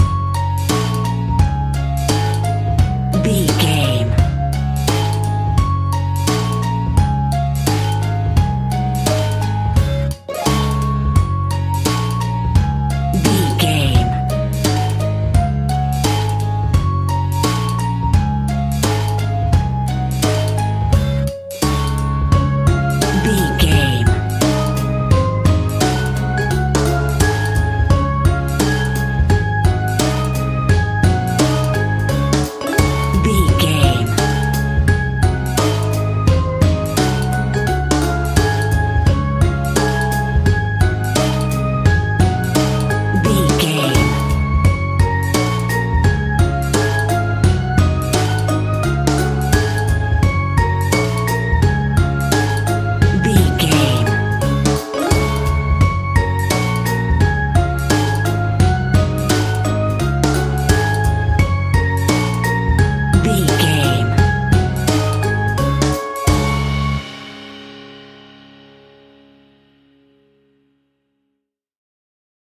Ionian/Major
D♭
childrens music
instrumentals
fun
childlike
cute
happy
kids piano